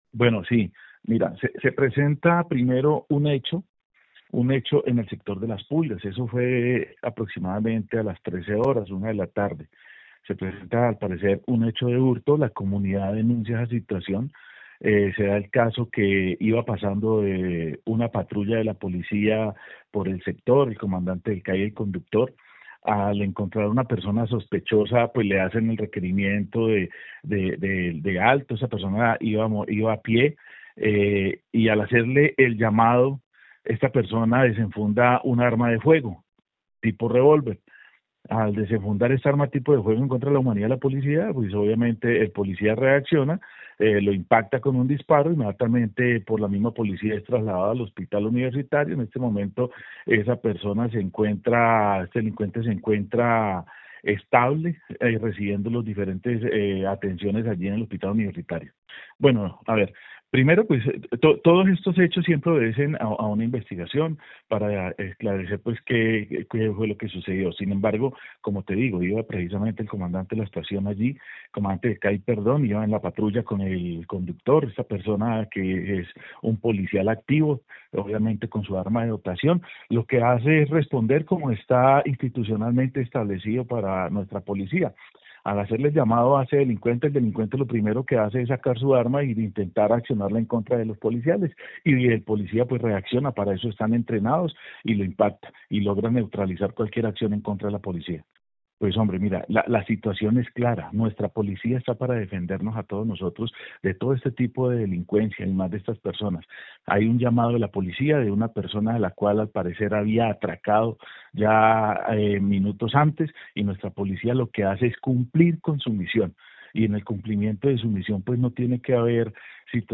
Gildardo Rayo, secretario del Interior de Bucaramanga